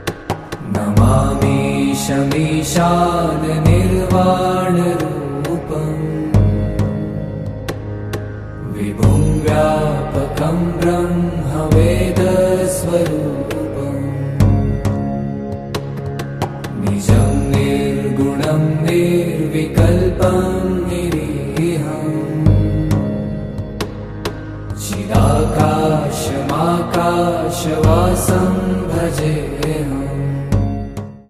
devotional bhajan
Sung in a soothing and powerful voice
Devotional Songs